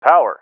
LSO-Power_Loud.ogg